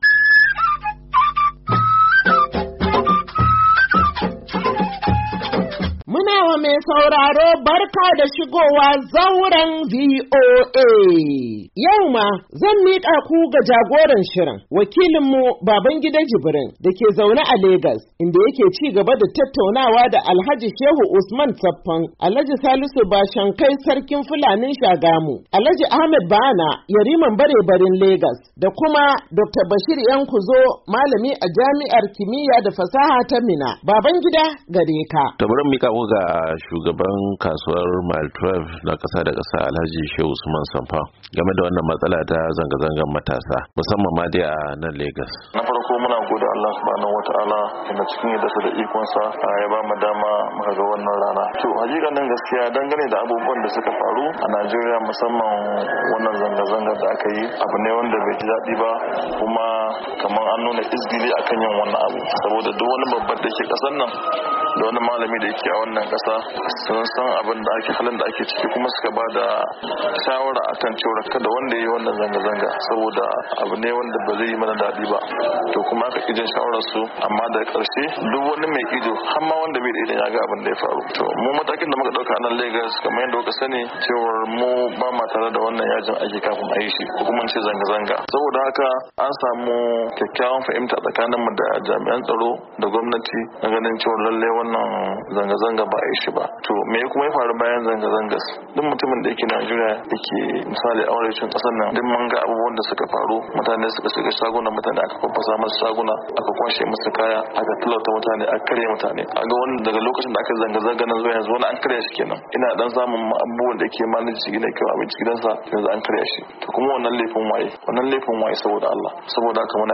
Mahalarta zauren sun baiyana ra'ayoyi mabanbanta kan zanga zangar matasa da aka yi kan wuyar rayuwa a cikin watan Agusta, Satumba28, 2024